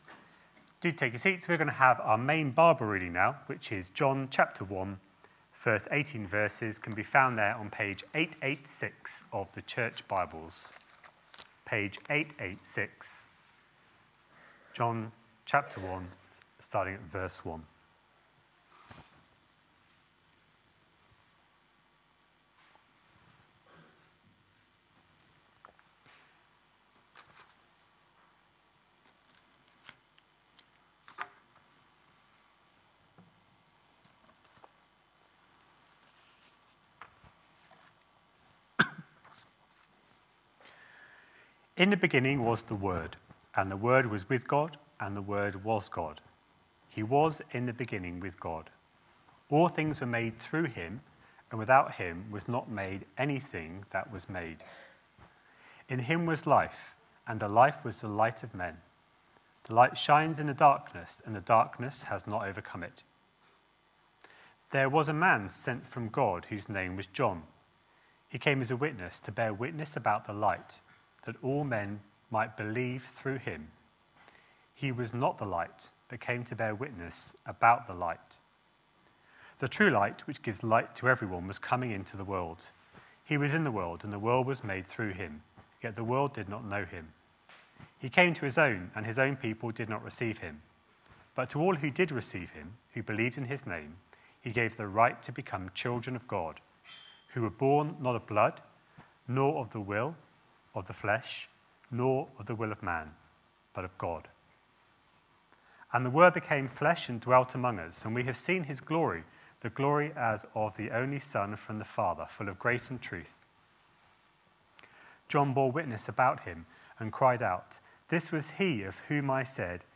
A sermon preached on 15th December, 2019, as part of our Christmas mini series series.